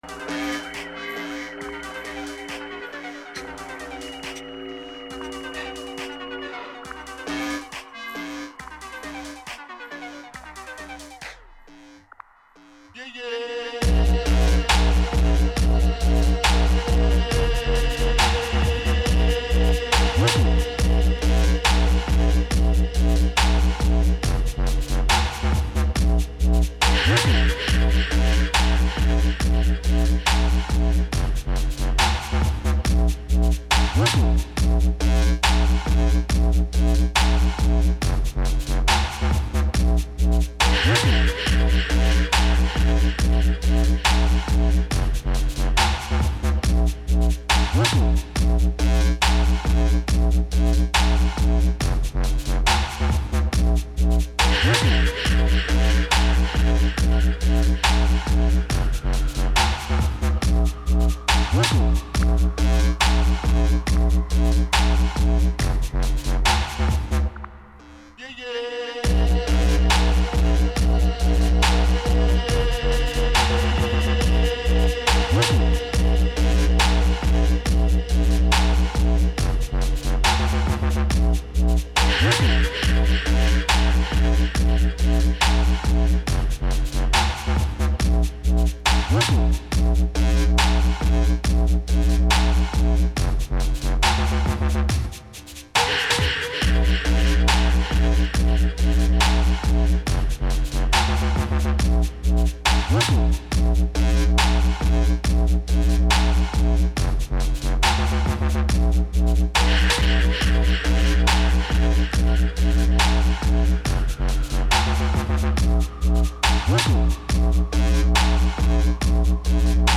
Dubstep Afterhours Mix (Direct Download)
FILE UNDER: Dubstep, UK Bass, UK Breakbeat
Afterhour tag sessions from 2006, playing ones, or twos amongst a handful of DJs.